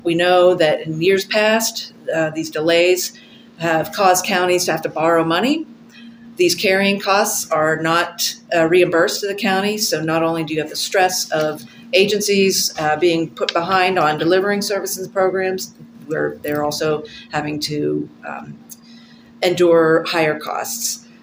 At Wednesday’s meeting, the Indiana County Commissioners expressed some concern with the lack of a state budget.